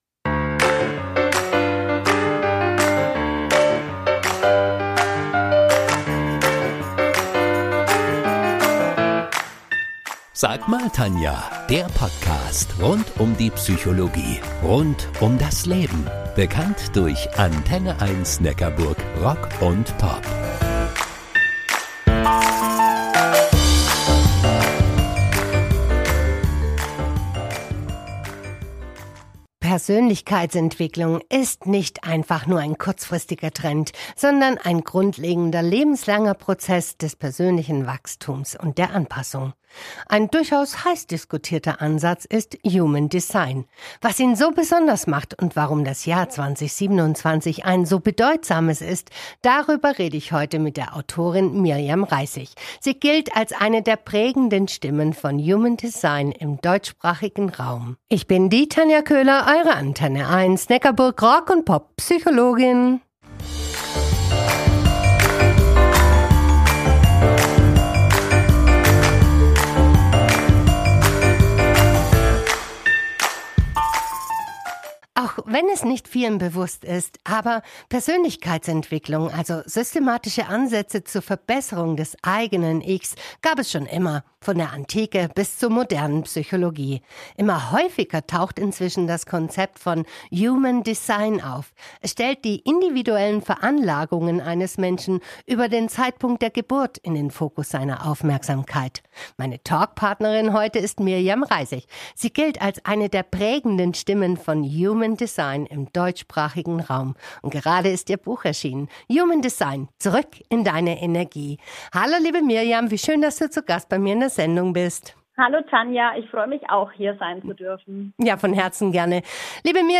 Im Gespräch
ist ein Mitschnitt der Original-Redebeiträge meiner 'Sendung Sag